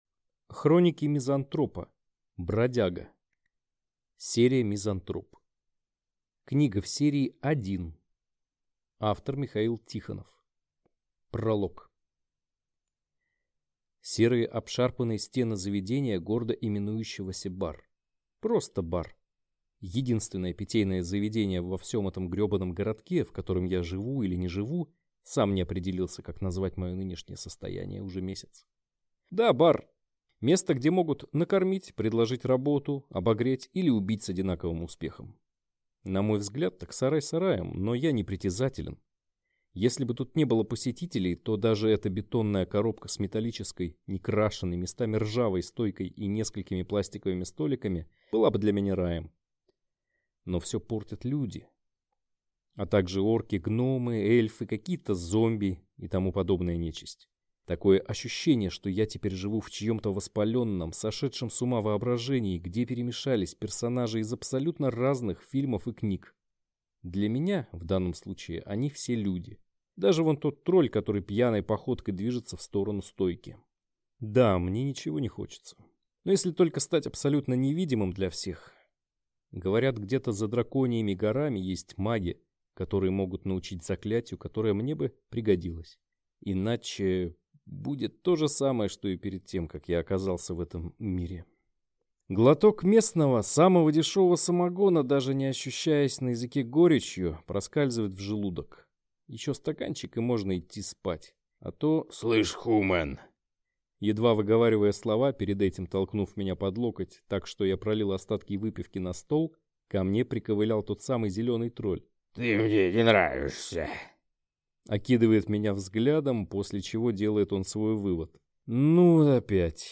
Аудиокнига Хроники мизантропа. Бродяга | Библиотека аудиокниг